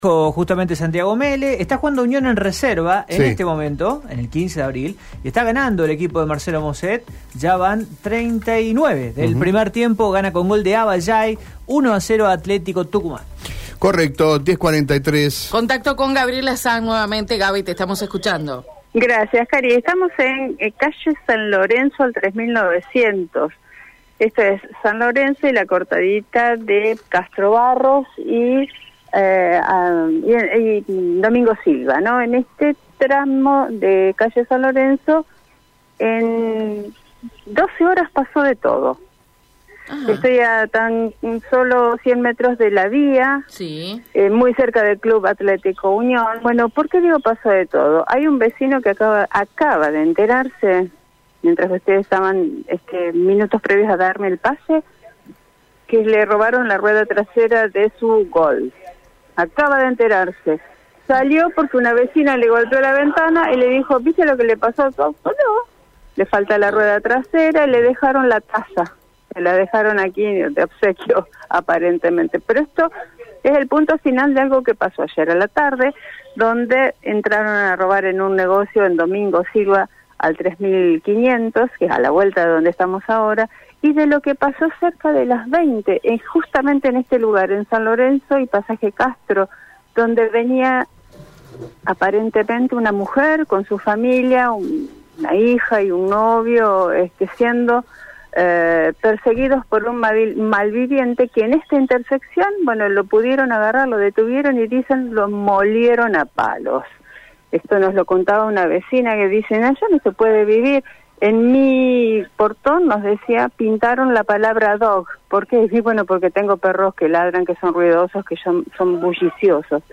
con las vecinas, donde relatan otros robos y la detención de un malviviente